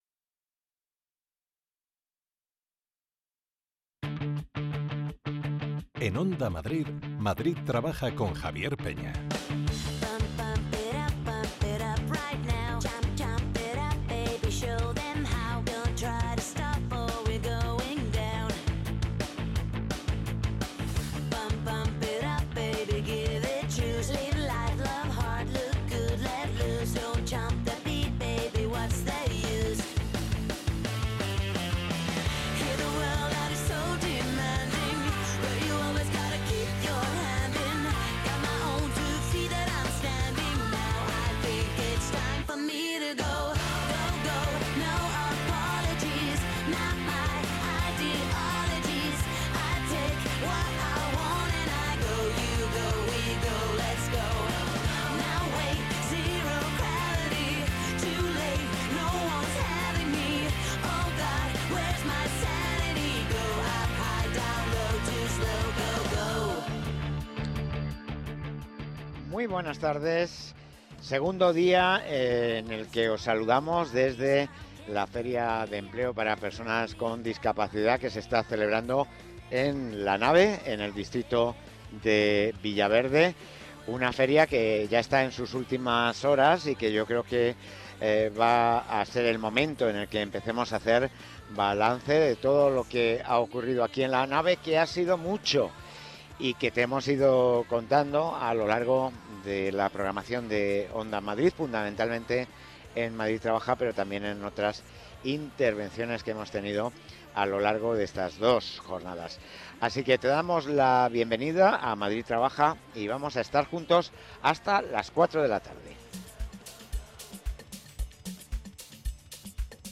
Escucha nuestro programa especial del 12 de noviembre de 2024 desde la XV Feria de Empleo para Personas con Discapacidad Intelectual